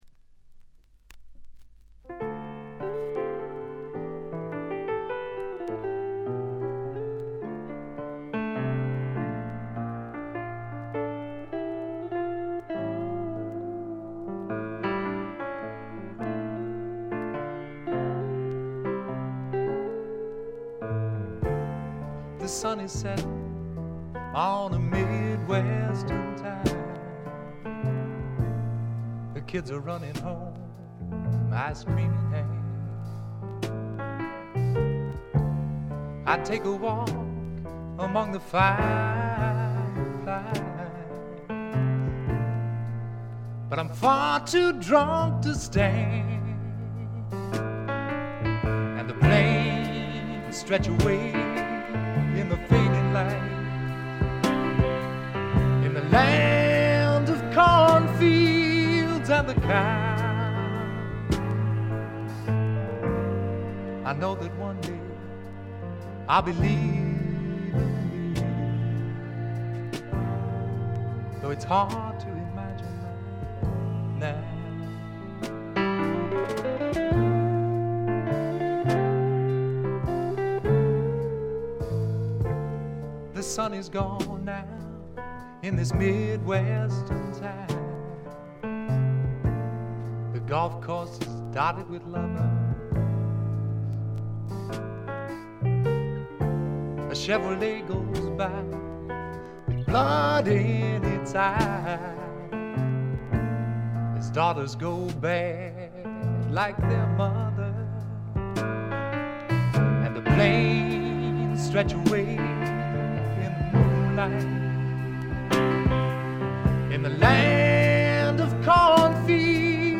軽微なチリプチ。散発的なプツ音少し。
シンプルなバックに支えられて、おだやかなヴォーカルと佳曲が並ぶ理想的なアルバム。
試聴曲は現品からの取り込み音源です。